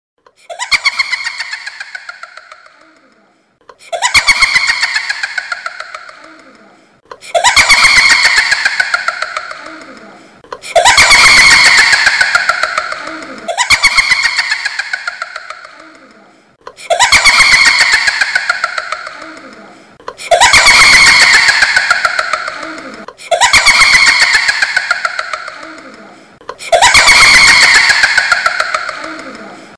เสียงนกคุ้มอืด MP3 ฟรี เสียงเรียก เข้า ผี
หมวดหมู่: เสียงเรียกเข้า